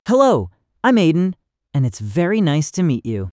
multilingual text-to-speech voice-cloning
A unified Text-to-Speech demo featuring three powerful modes: Voice, Clone and Design
"mode": "custom_voice",